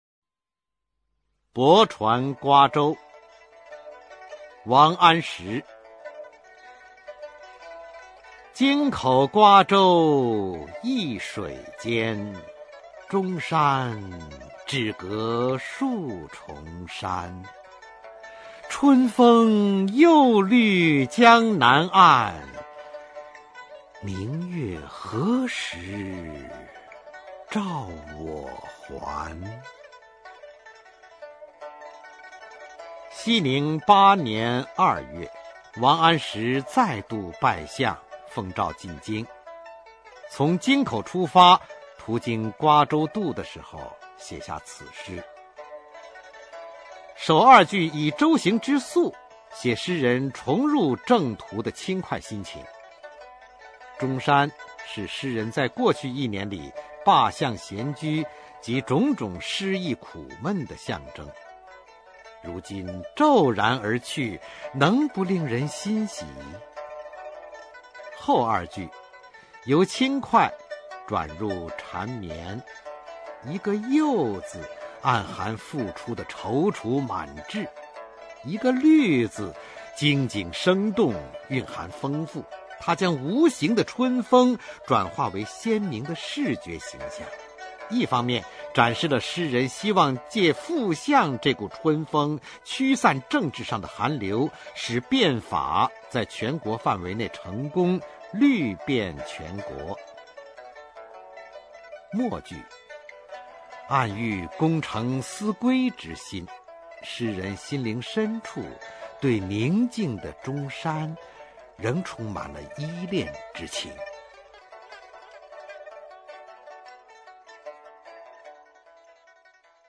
[宋代诗词诵读]王安石-泊船瓜洲（男） 宋词朗诵